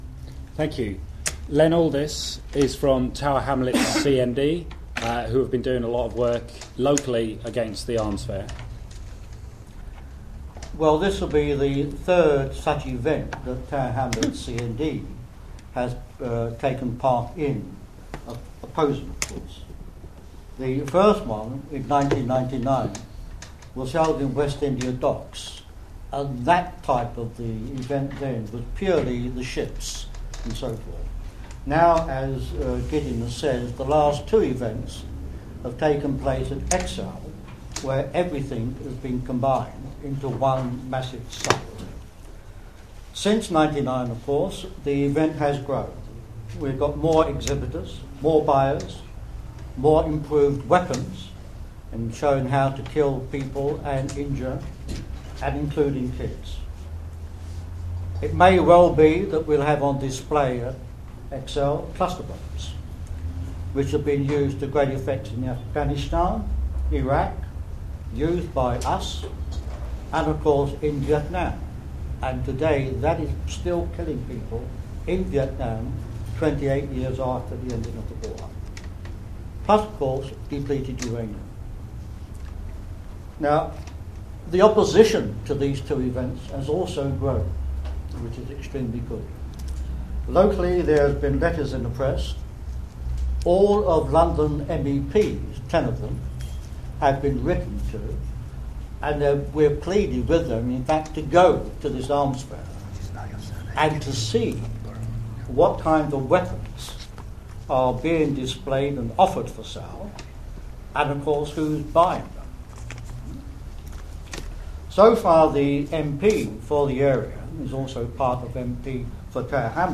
DSEi Audio - Press Conference led by CAAT - Fri 5th Sept
Five sections of audio from the press conference held today at Friends Meeting House in London.